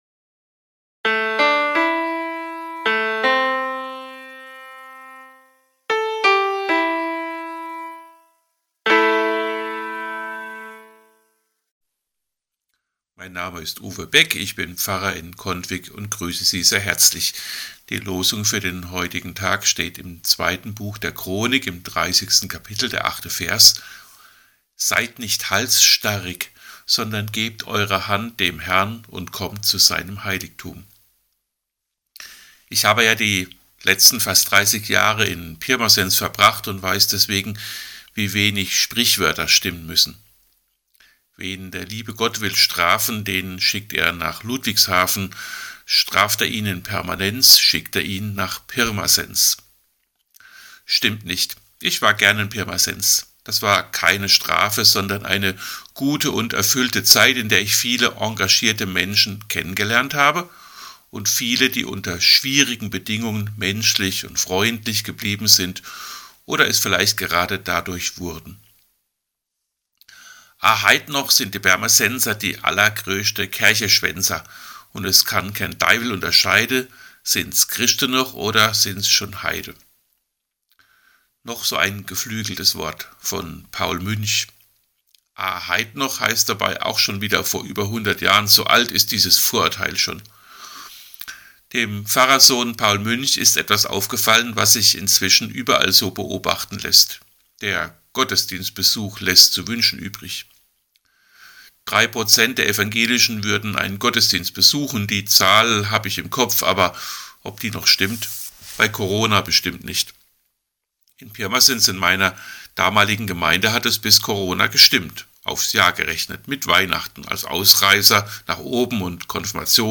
Losungsandacht für Samstag, 19.01.2022